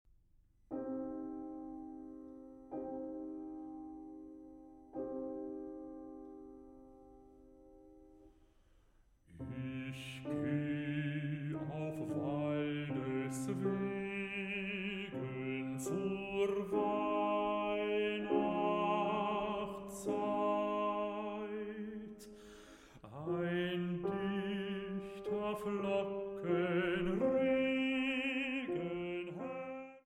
Bariton
Klavier